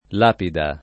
lapida [ l # pida ]